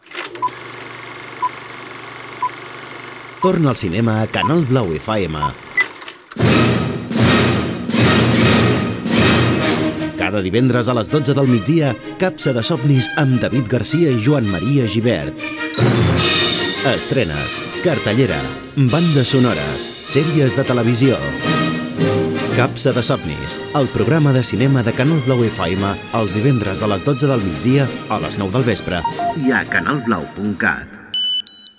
Senyals horaris, careta del programa.